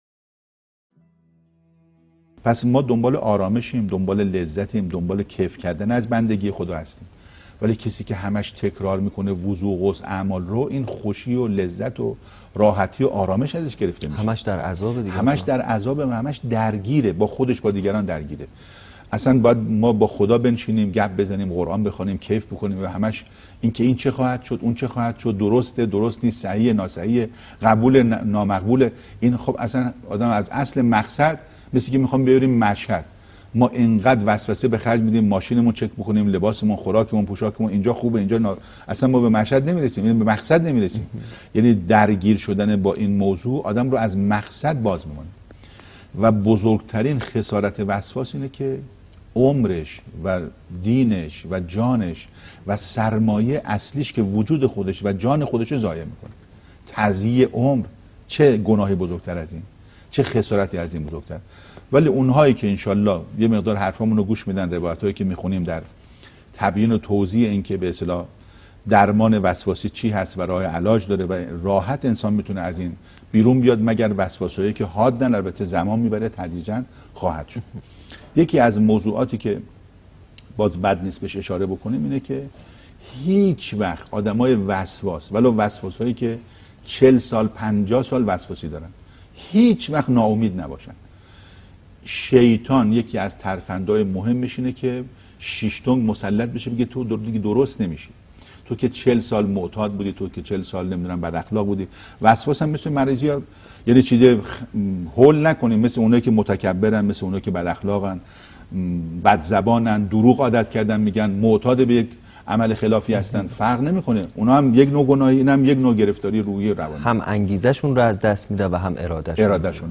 سخنرانی | درمان وسواس چیست؟
گفتگوی تلوزیونی